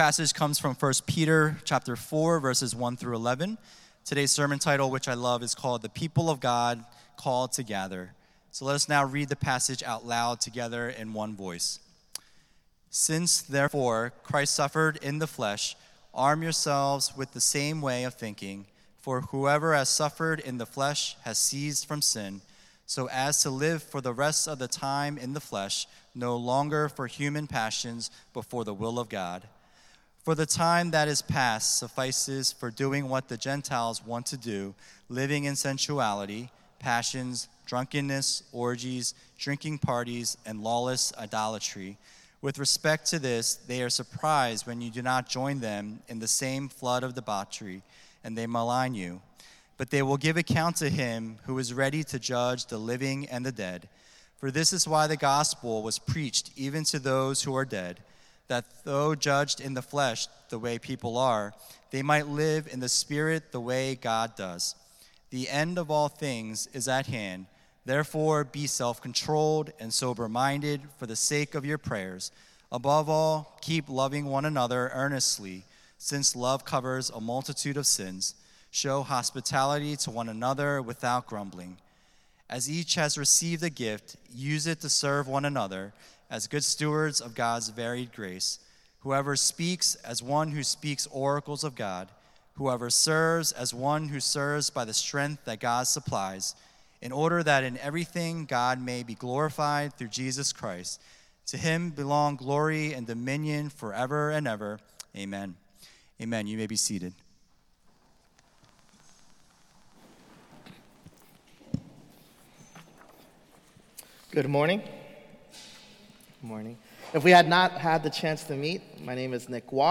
Weekly Sermons from Renewal Main Line